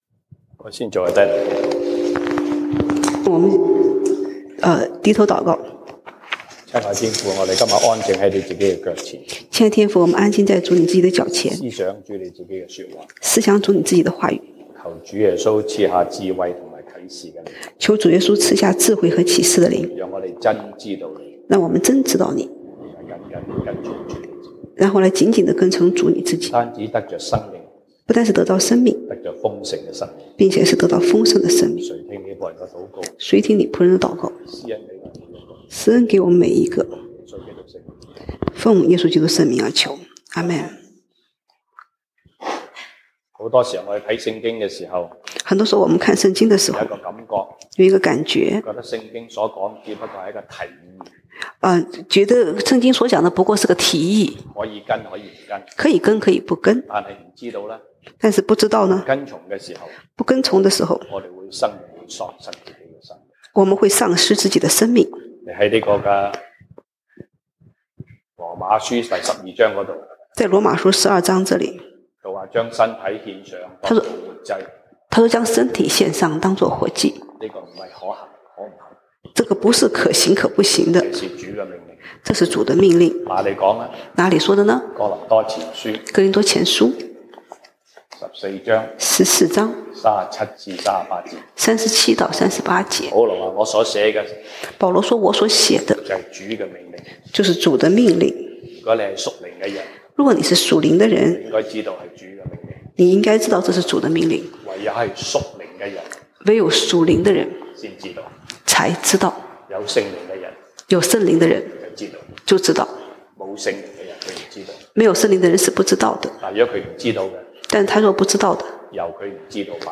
西堂證道(粵語/國語) Sunday Service Chinese: 豐盛的人生